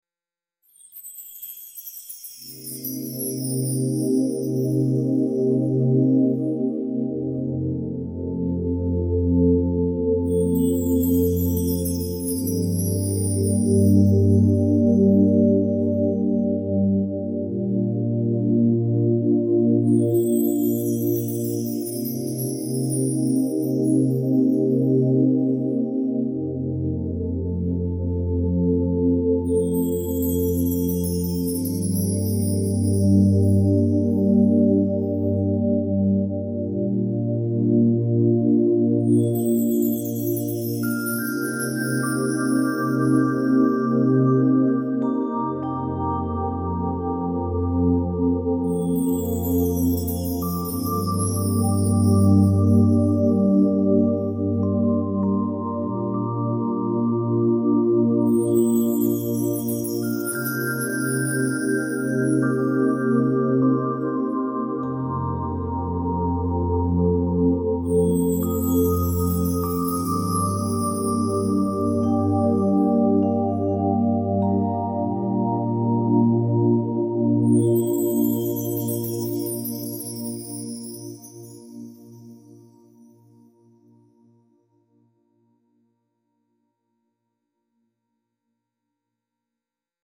peaceful meditation-style music with gentle pads and delicate chimes